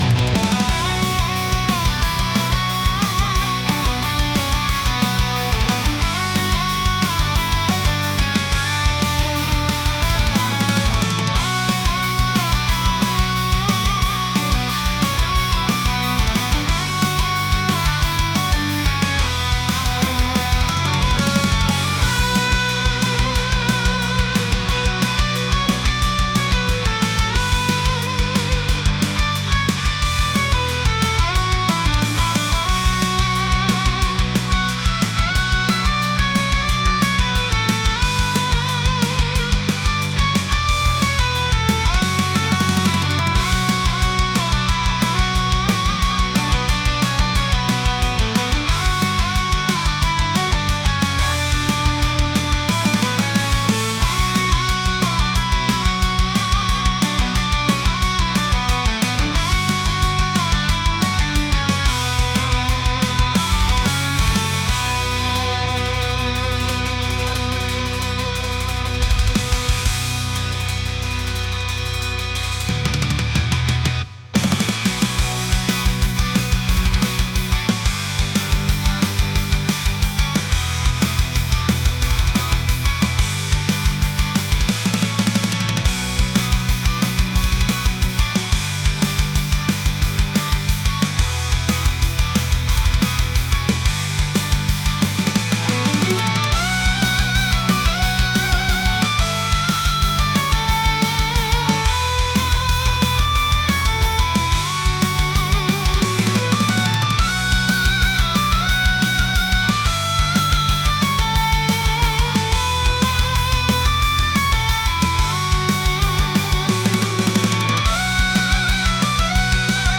metal | heavy | aggressive